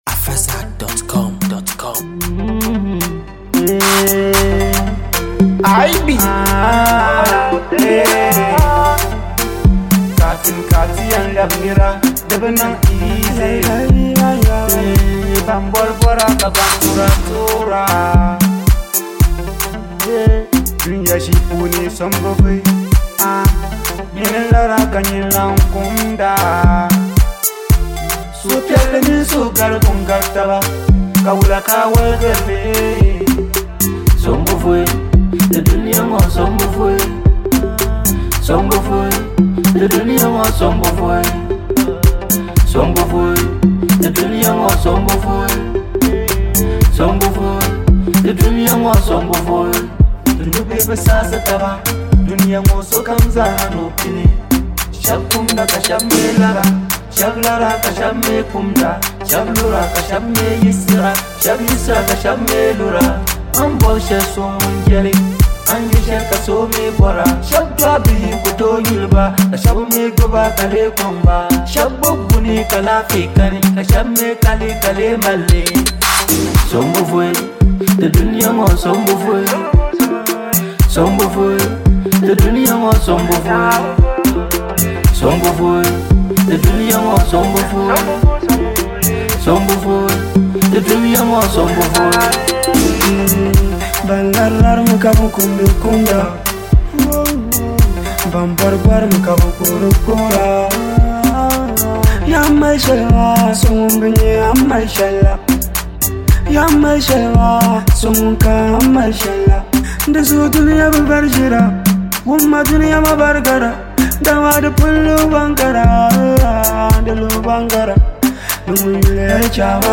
With its catchy vibe and strong lyrical presence